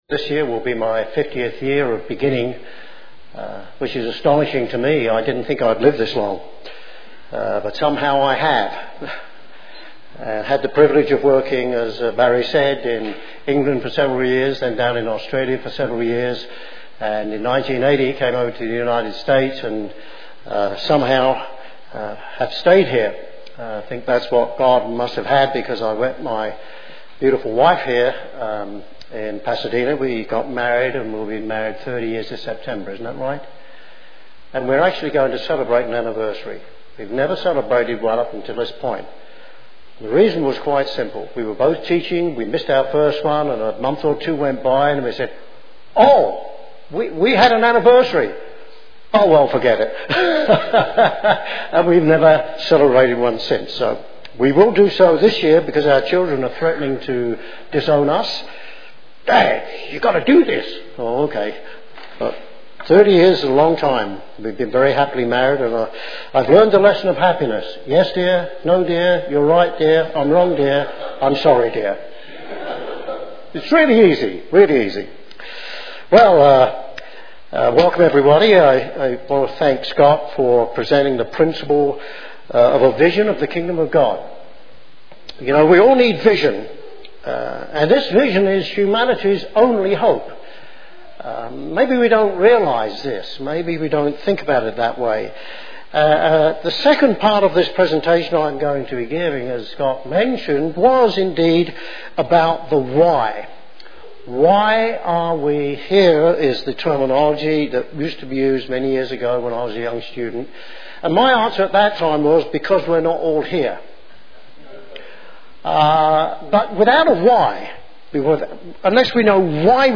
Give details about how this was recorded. Given in Colorado Springs, CO